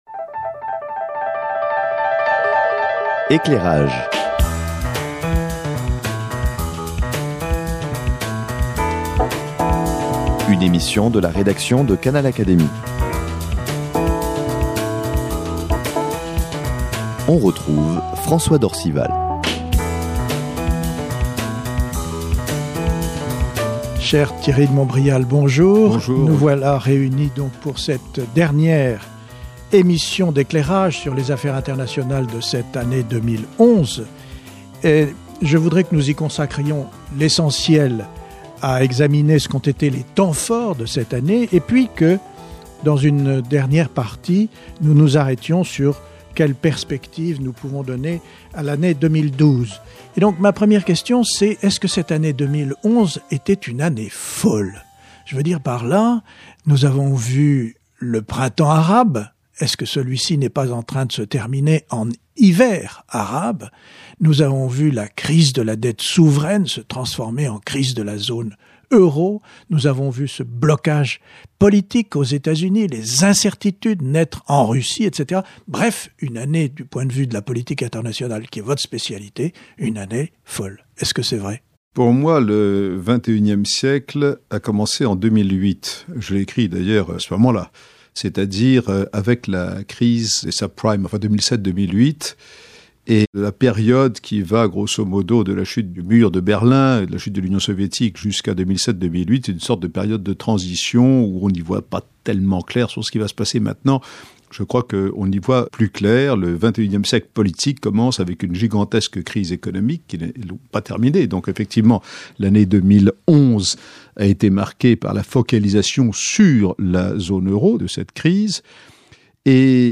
Thierry de Montbrial et François d’Orcival, membres de l’Académie des sciences morales et politiques, se sont retrouvés le mercredi 21 décembre 2011 pour un tour d’horizon de l’année écoulée.